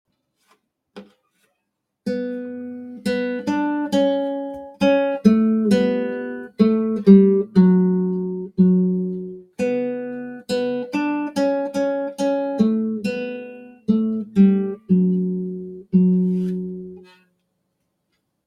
guitarra.mp3